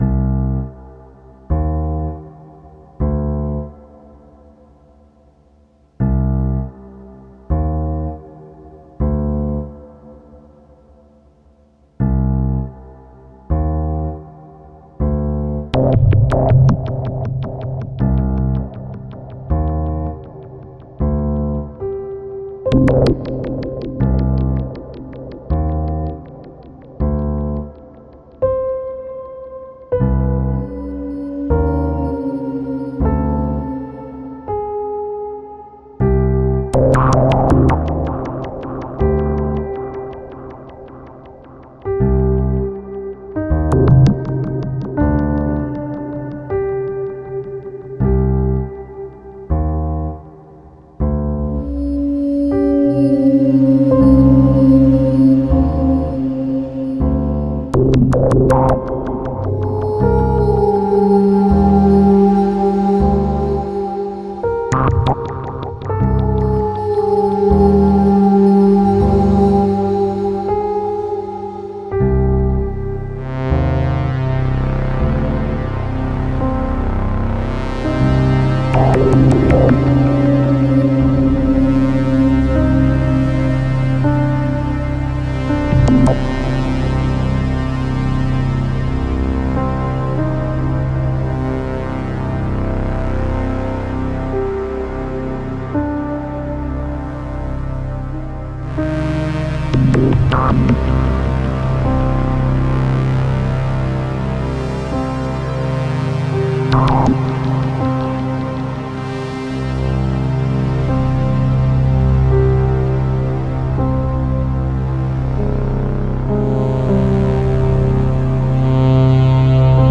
Imrovisationen